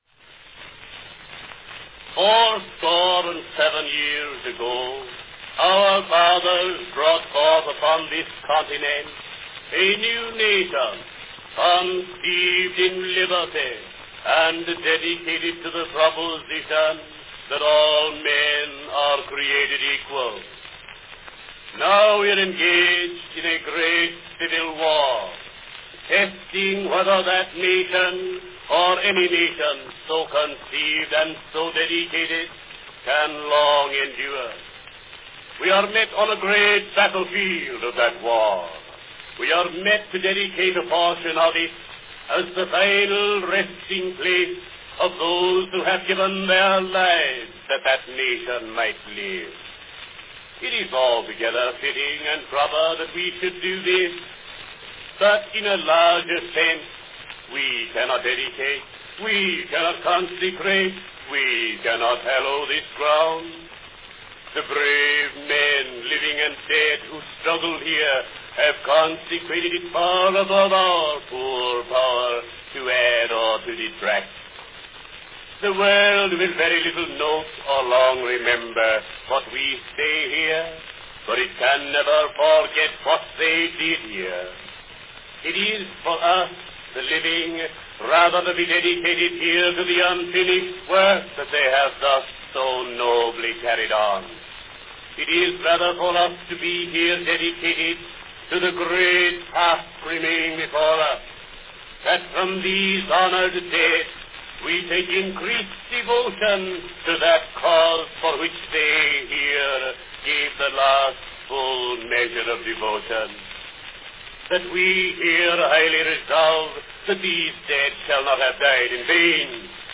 President Lincoln's Speech at Gettysburg, recited by Len Spencer.
Company Edison's National Phonograph Company
Category Talking
Rare for most speeches, Lincoln's historic address fits perfectly in a two-minute wax cylinder recording.
The date of this cylinder is somewhat uncertain, but it seems to be a re-make of Spencer's September 1902 recording.